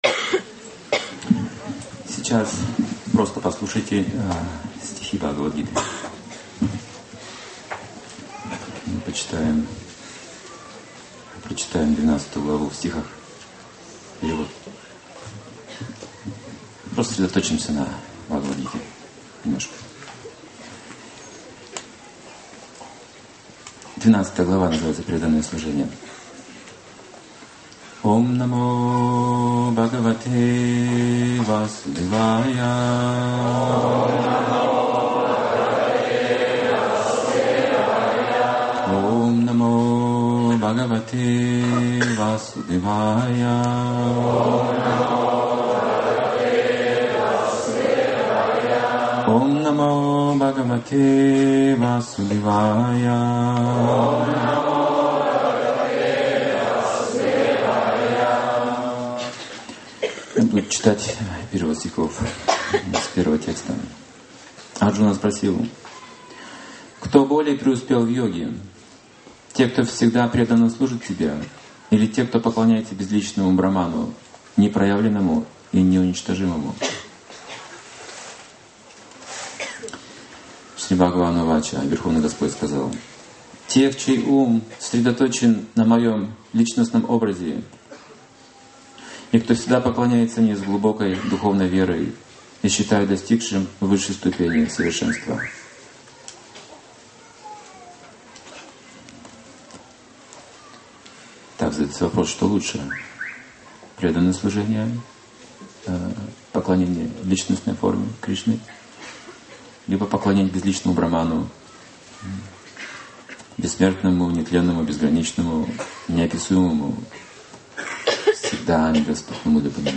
Бхагавад-гита "Лекция по 12 главе"